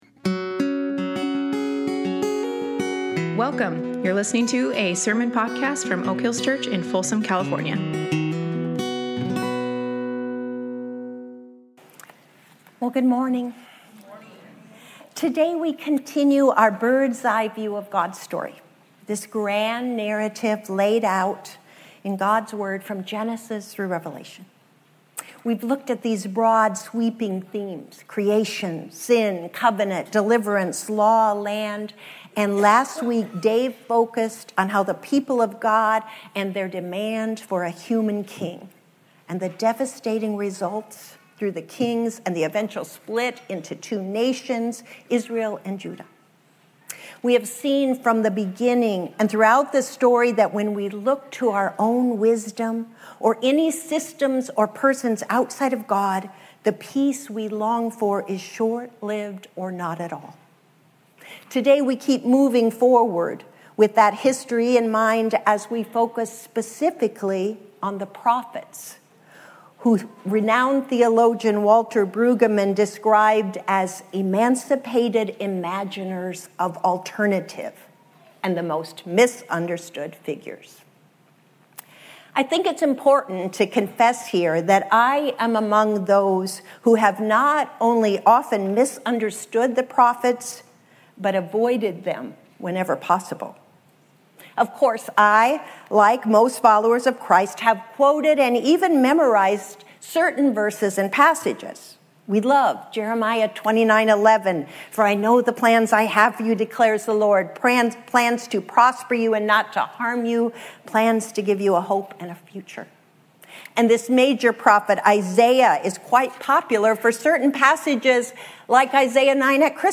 Oak Hills Church in Folsom, CA presents weekly messages about the reality of God and a vision of life under His guidance and leadership